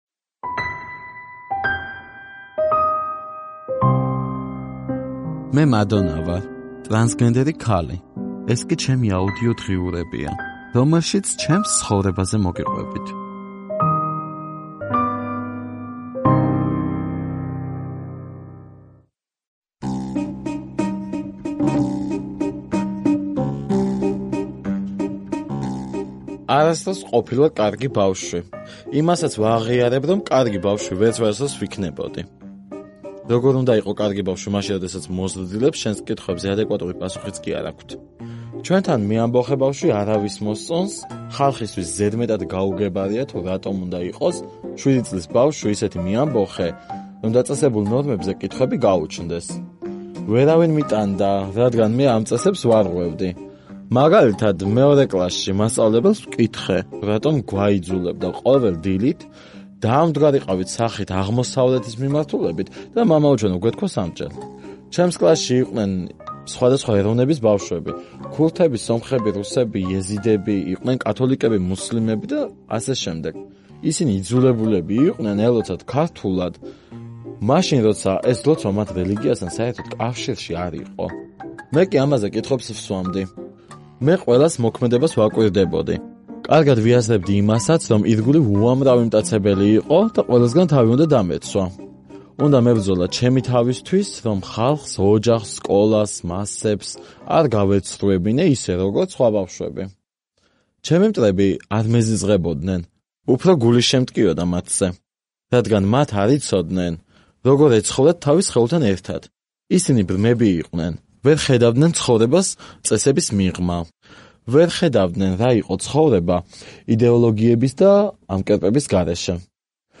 ტრანსგენდერი ქალი.